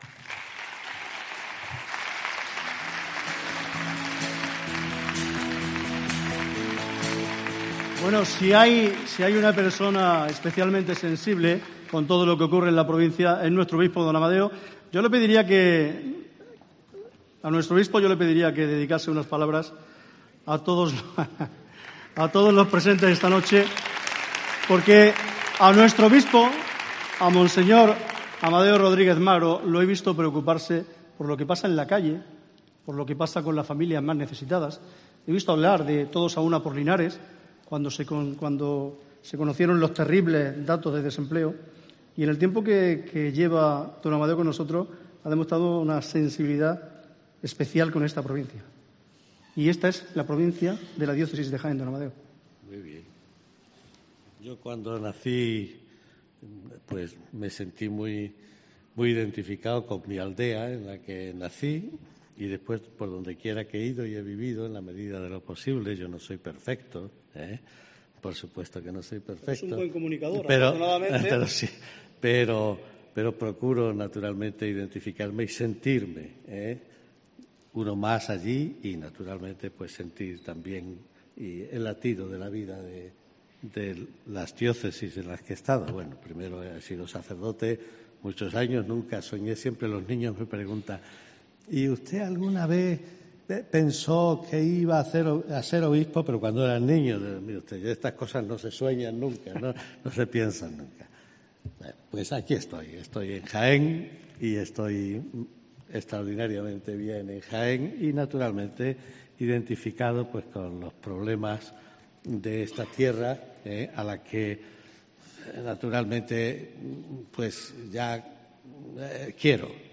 EN EL HOTEL HO CIUDAD DE JAÉN
Intervención del Obispo de Jaén, D. Amadeo Rodríguez